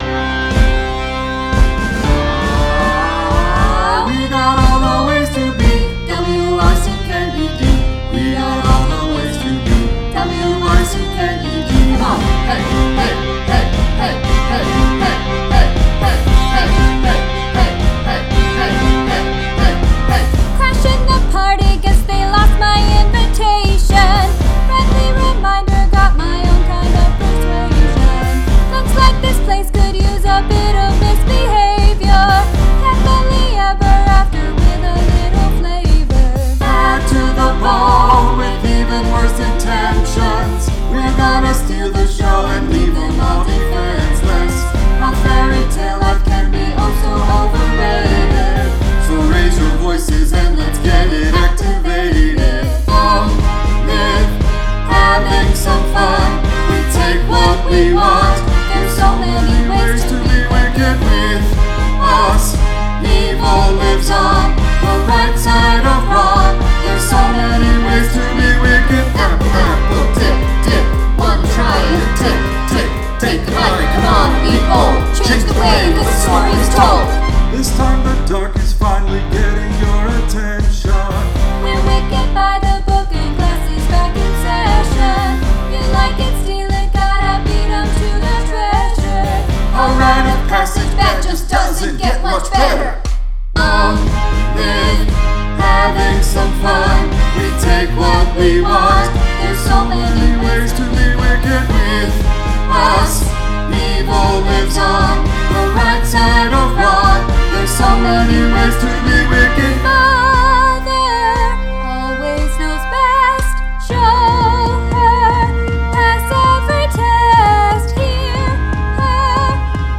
SAB